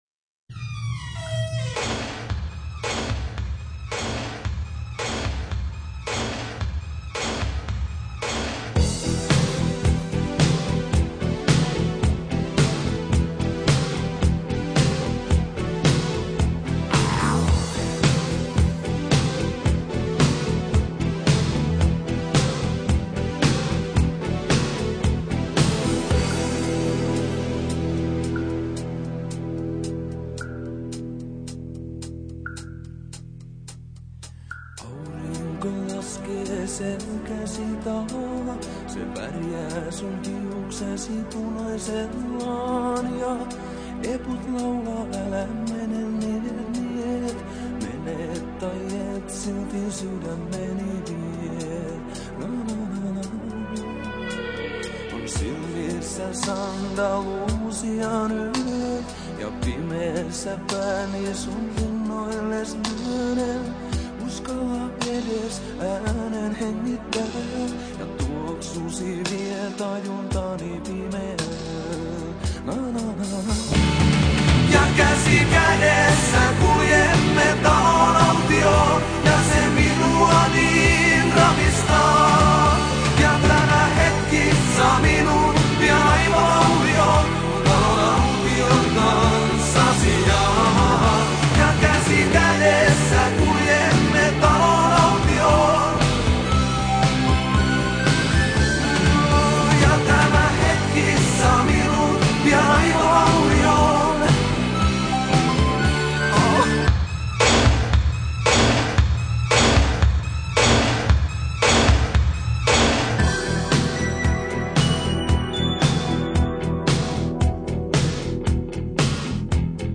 Die Rockband